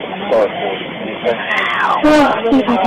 These 2 EVP's were recorded at Allatoona Pass, where we have done many investigations. These are typical EVP's in that we know the voices weren't ours, yet we can't make out what they are saying.